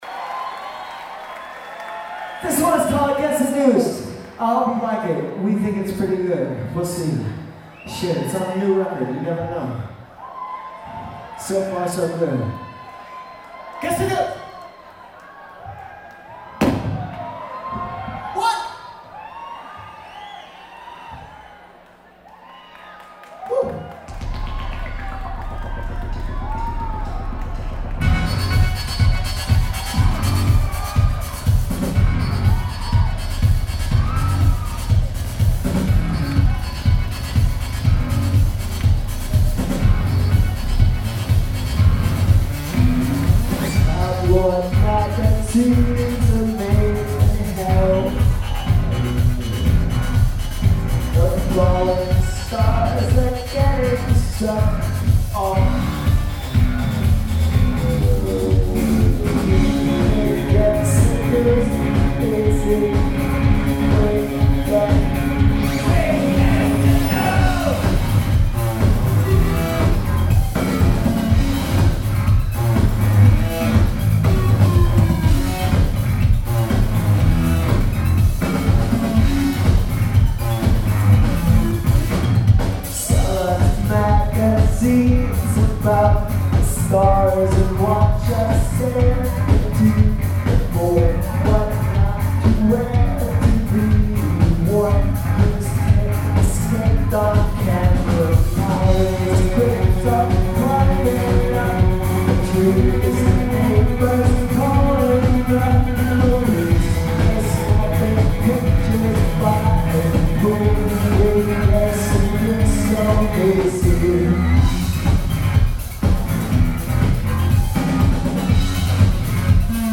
This is a new song from the August show at Terminal 5.